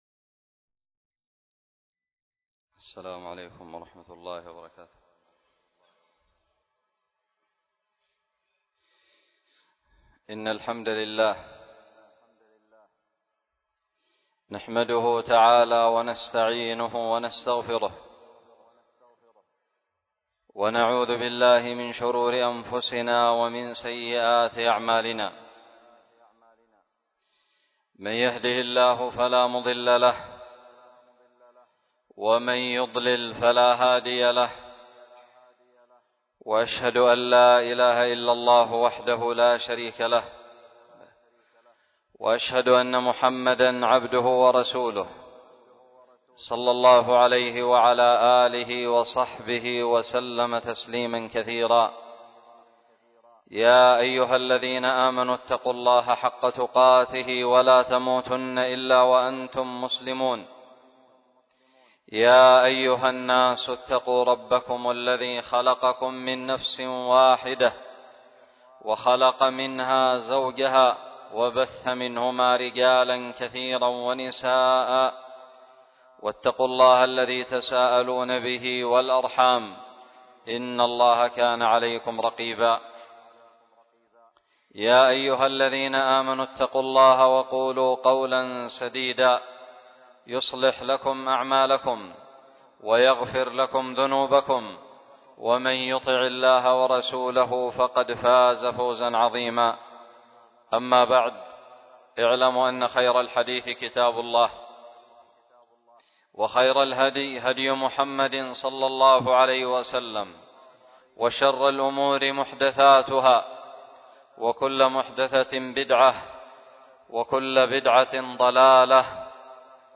خطب الجمعة
ألقيت بدار الحديث السلفية للعلوم الشرعية بالضالع